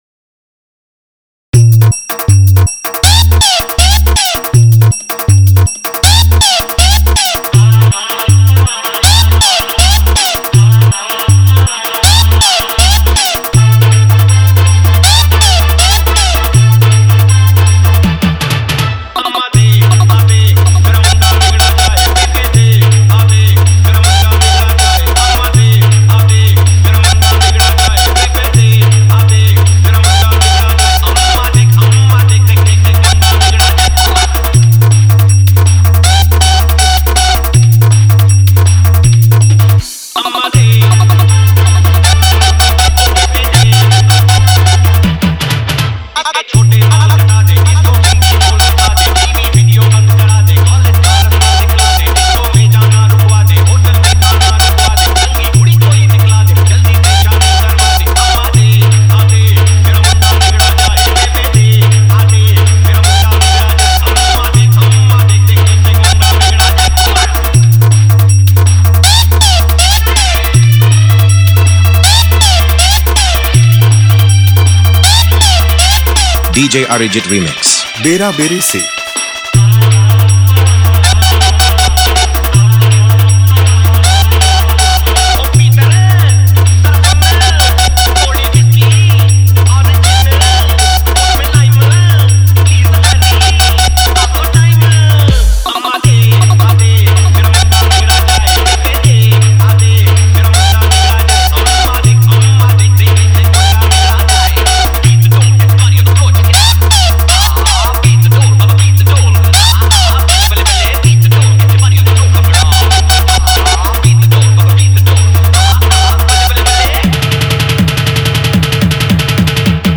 Vibration Mix